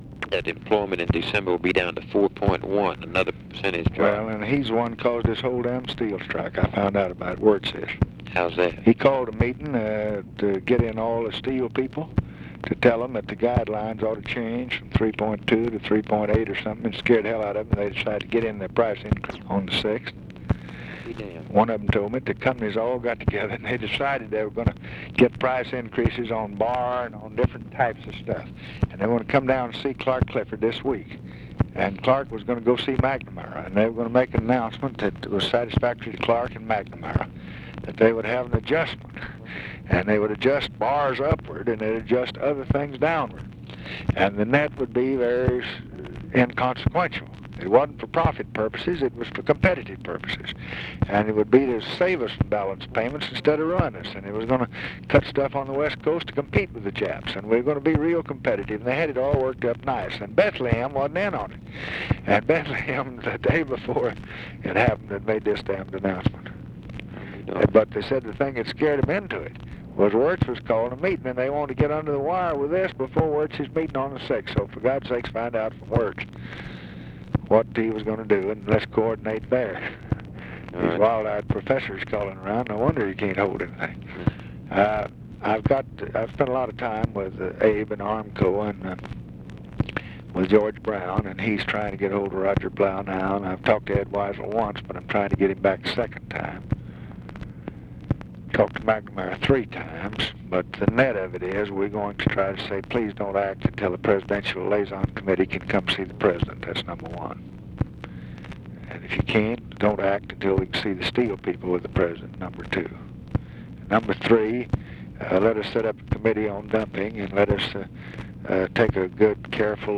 Conversation with BILL MOYERS, January 4, 1966
Secret White House Tapes